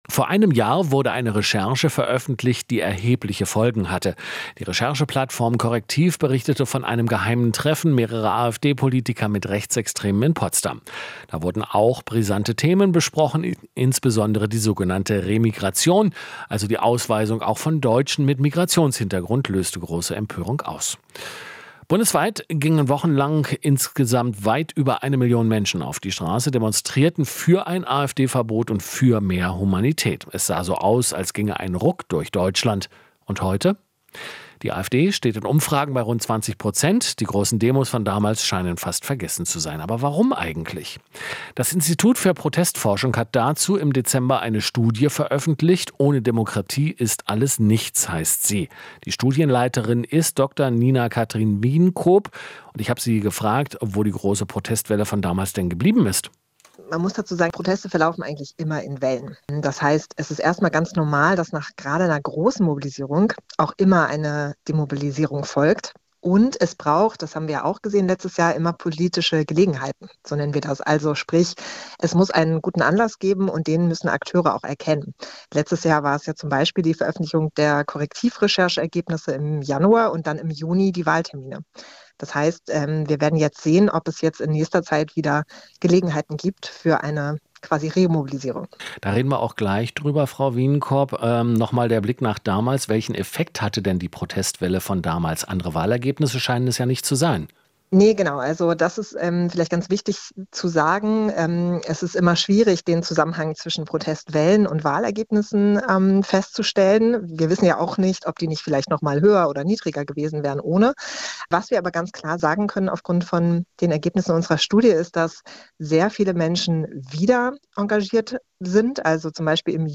Interview - Ein Jahr nach der Correctiv-Recherche: Was haben die Proteste gebracht?